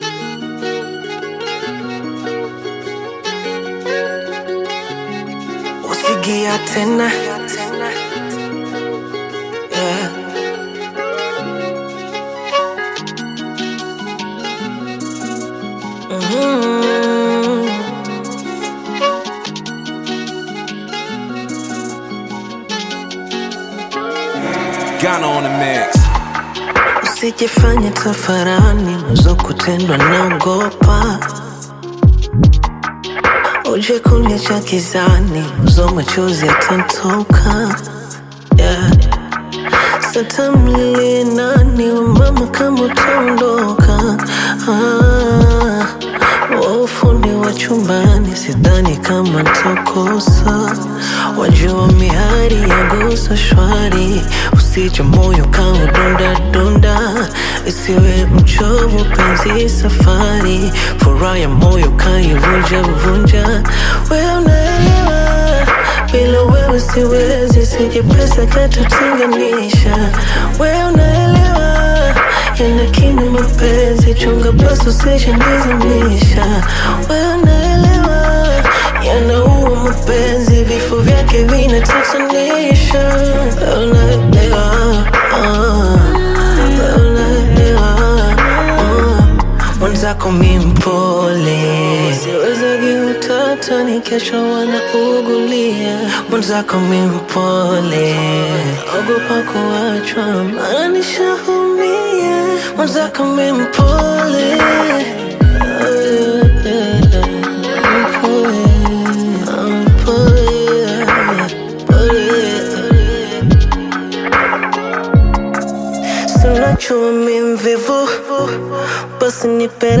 Bongo Flava music track
Tanzanian Bongo Flava artist, singer, and songwriter
Bongo Flava